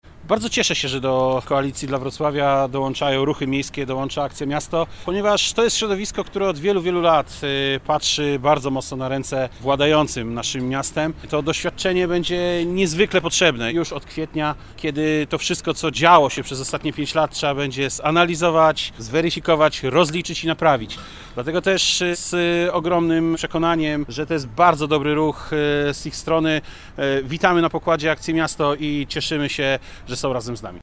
Radny Michał Kwiatkowski z osiedla Powstańców Śląskich, dodaje że postulat rzetelności będzie istotnym argumentem w czasie nadchodzących wyborów samorządowych.